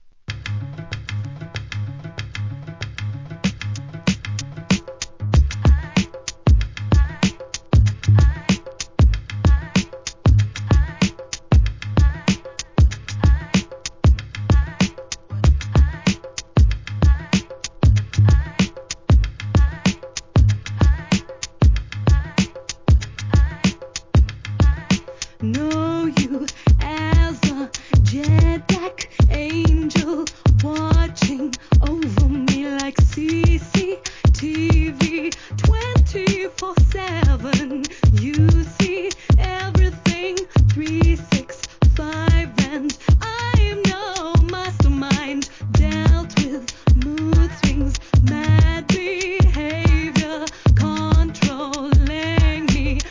HIP HOP/R&B
UKダウンテンポR&B!